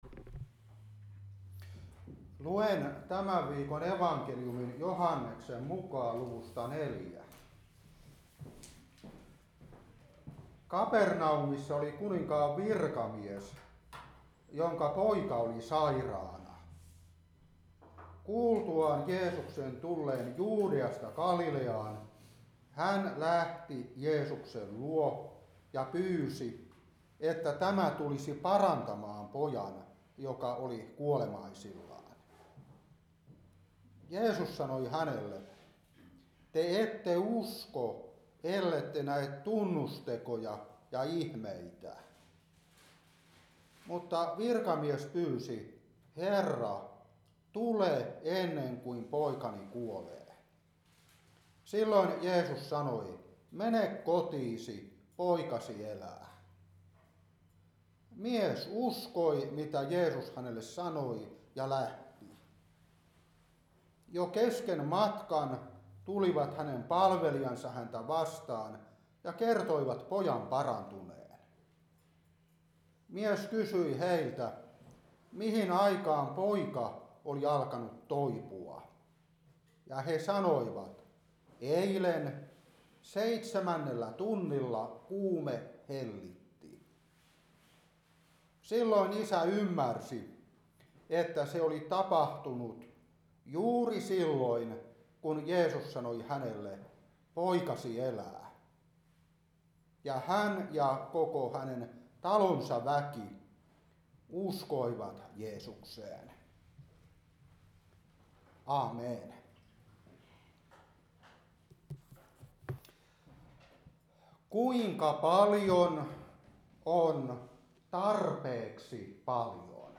Seurapuhe 2025-11.